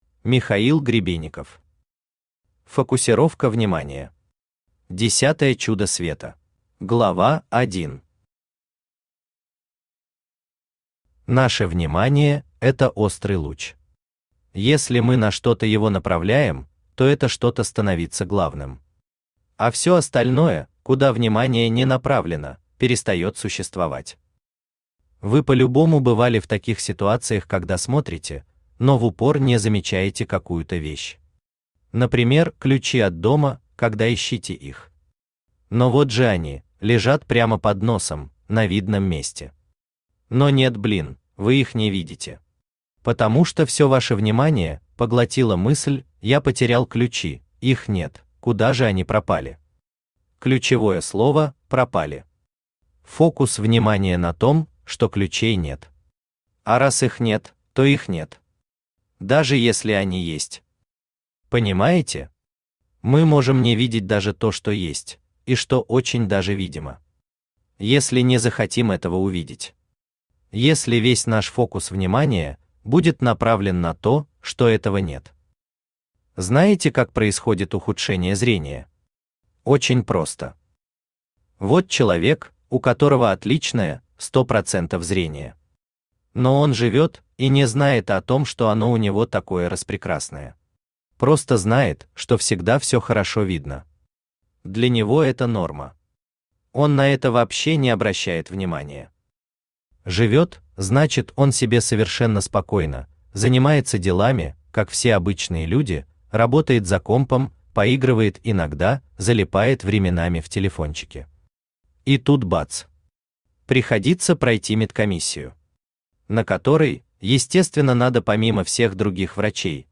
Аудиокнига Фокусировка внимания. Десятое чудо света | Библиотека аудиокниг
Десятое чудо света Автор Михаил Валерьевич Гребенников Читает аудиокнигу Авточтец ЛитРес.